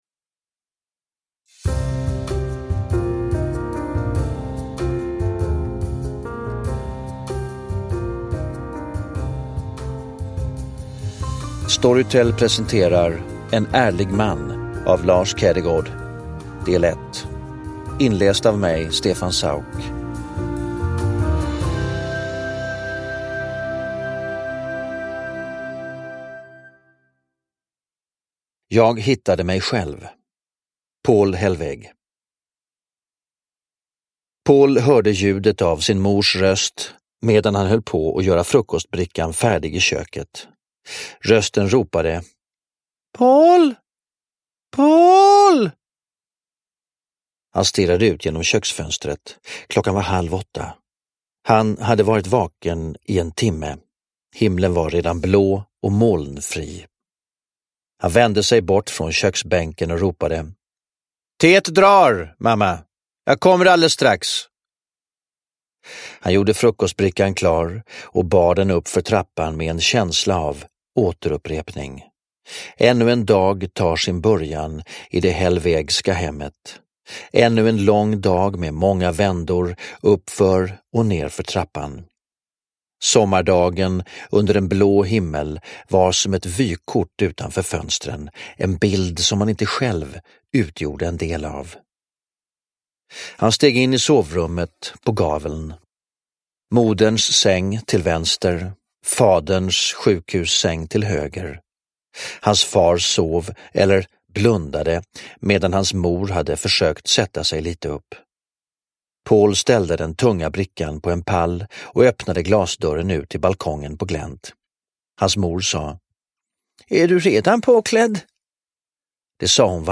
En ärlig man - S1E1 – Ljudbok – Laddas ner
Uppläsare: Stefan Sauk